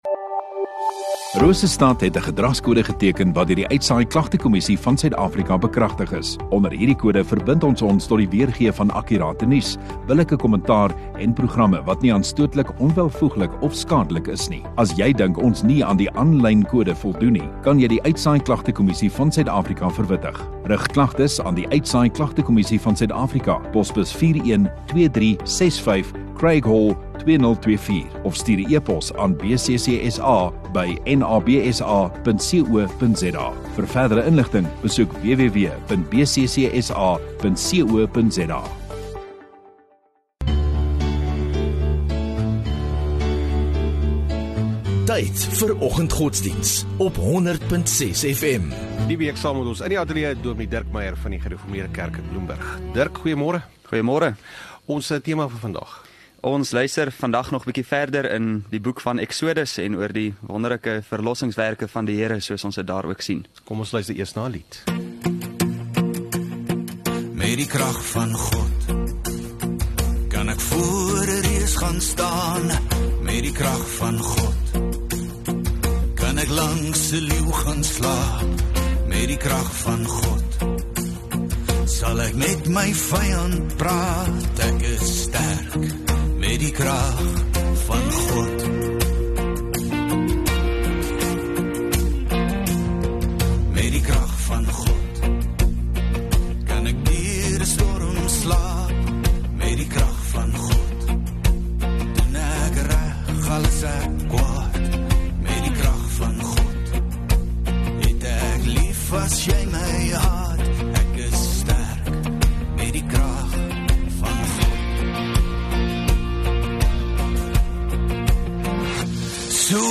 24 Sep Disndag Oggenddiens